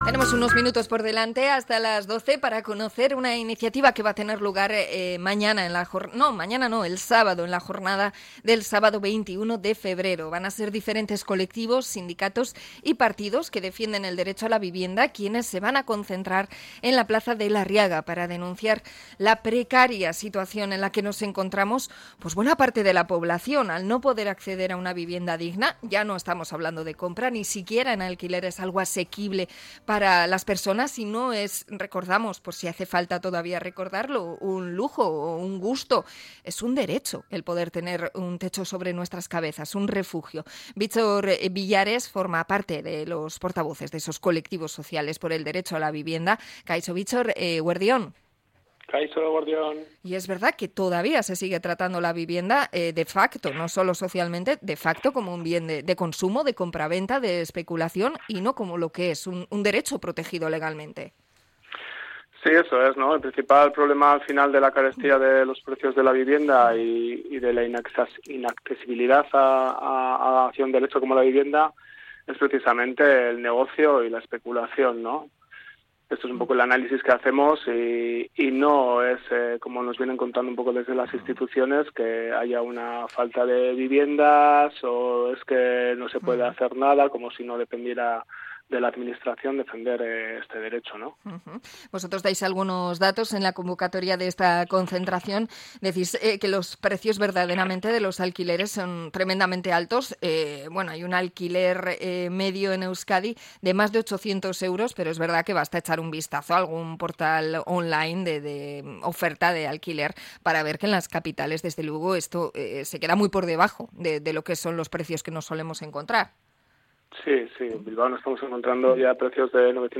Entrevista a los convocantes de una concentración por la vivienda en Bilbao